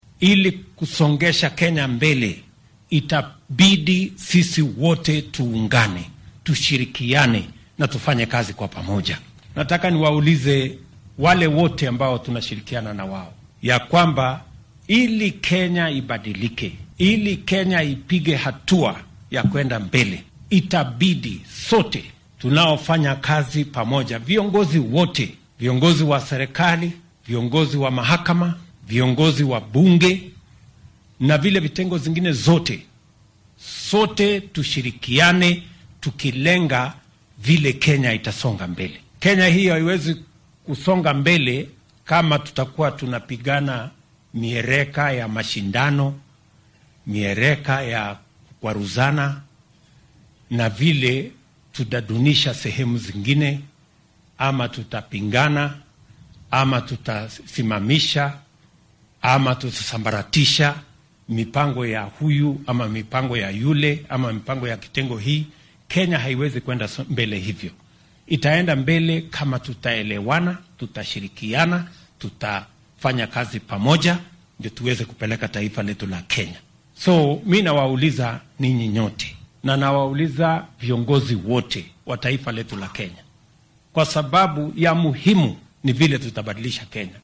Arrintan ayuu ka sheegay kaniisadda AIC Sugutek ee deegaanka Soy ee ismaamulka Uasin Gishu.